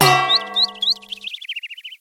Звуки удара по голове
На этой странице собраны различные звуки ударов по голове – от мультяшных до максимально реалистичных.